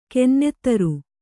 ♪ kennettaru